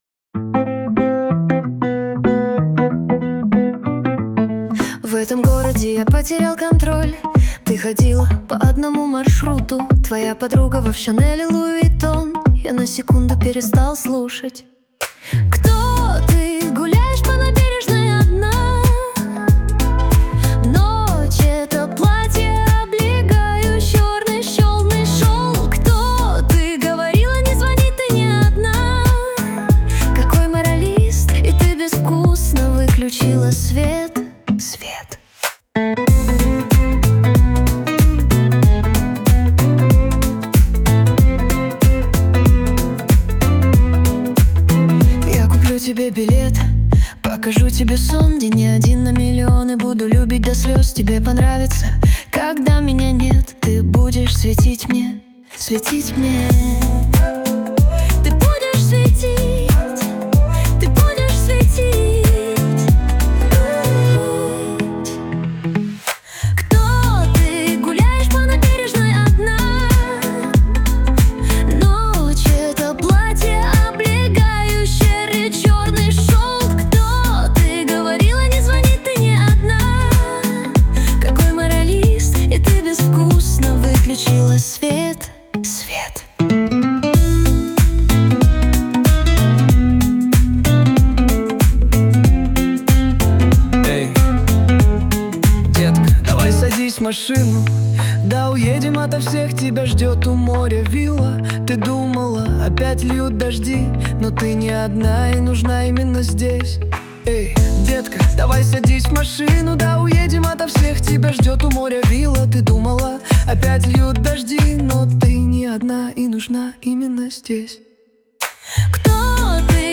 RUS, Romantic, Lyric, Dance, Funk | 17.03.2025 16:41